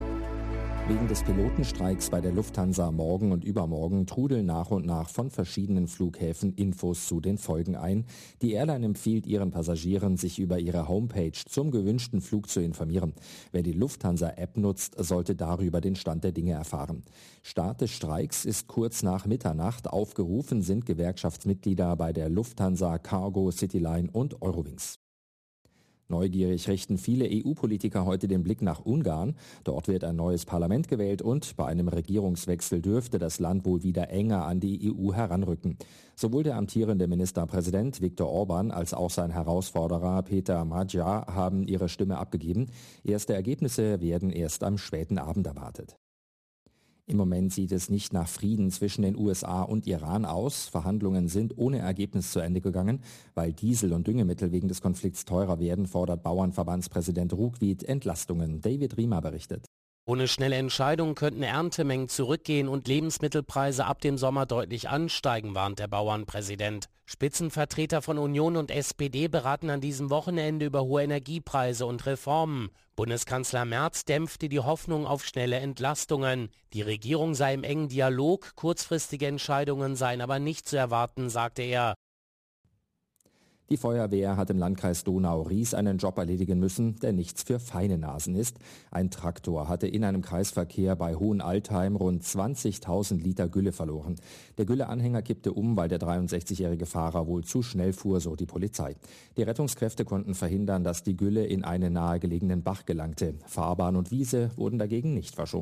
Nachrichten , Nachrichten & Politik